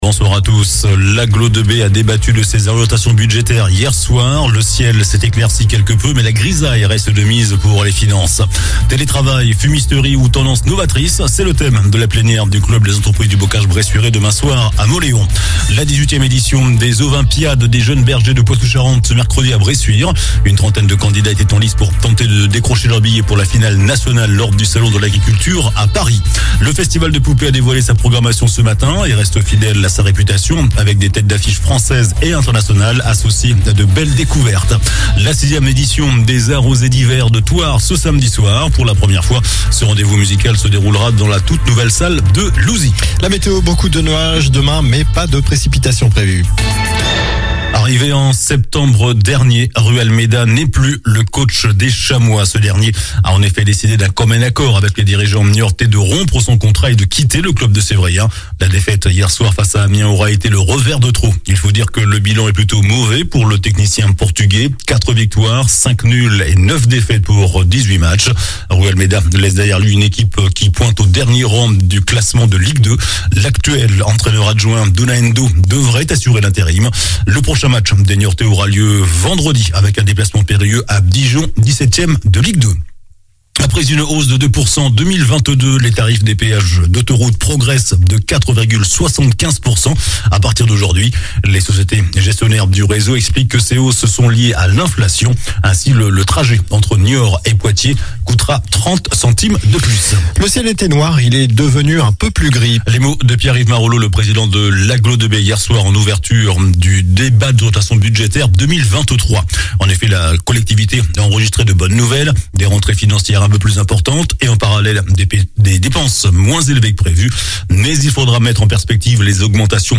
JOURNAL DU MERCREDI 01 FEVRIER ( SOIR )